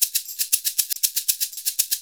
Index of /90_sSampleCDs/USB Soundscan vol.56 - Modern Percussion Loops [AKAI] 1CD/Partition D/04-SHAKER119